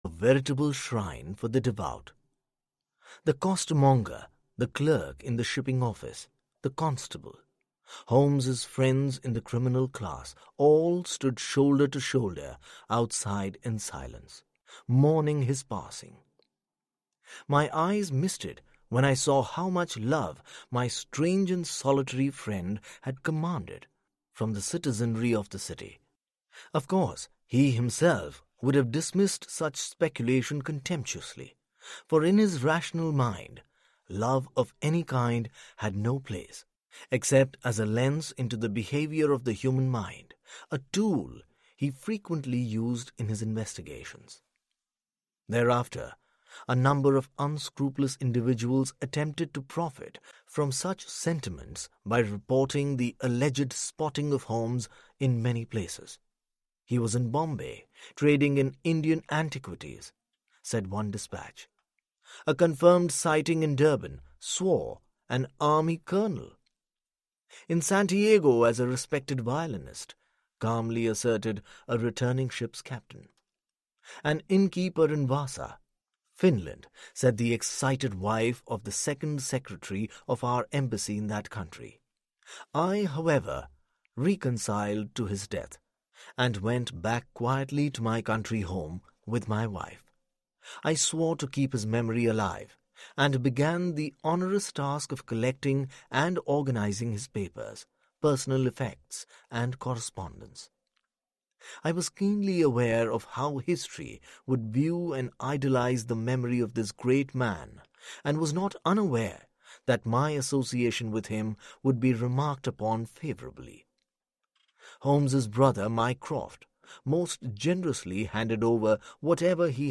Sherlock Holmes in Japan - Vasudev Murthy - Hörbuch